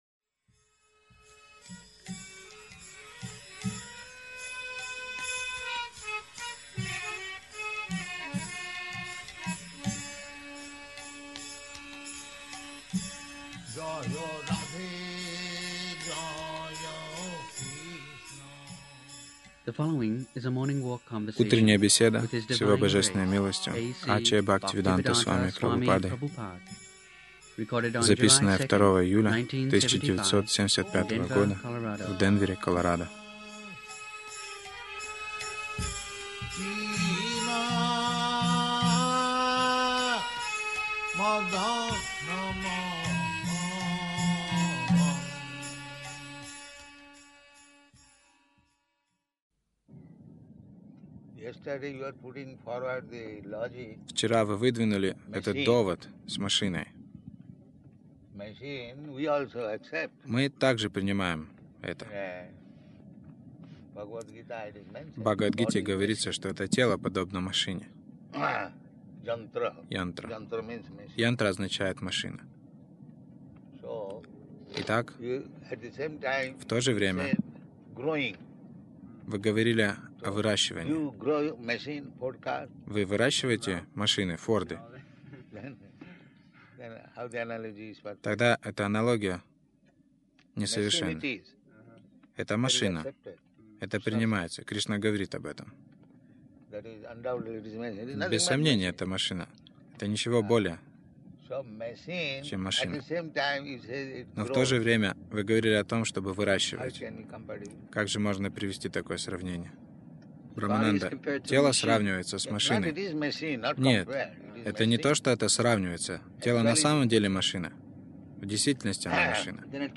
Милость Прабхупады Аудиолекции и книги 02.07.1975 Утренние Прогулки | Денвер Утренние прогулки — Каждую секунду — новое тело Загрузка...